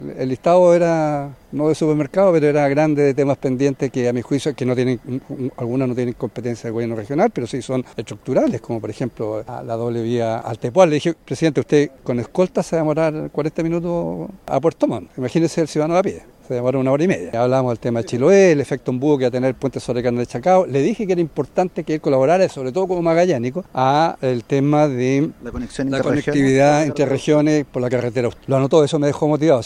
La máxima autoridad política en la región, al salir de la cita, conversó con Radio Bío Bío y entregó algunos detalles de lo dialogado con el jefe de Estado.